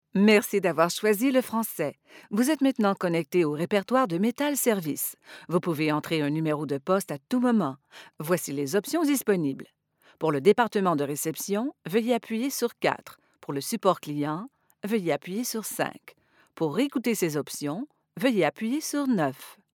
-Neumann TLM 103 Microphone
-Professional sound isolation recording booth